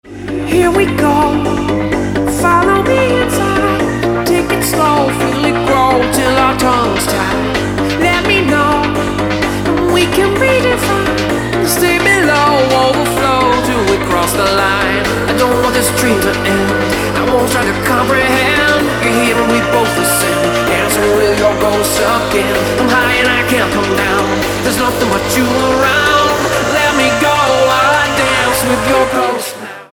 • Качество: 256, Stereo
поп
мужской вокал
club
vocal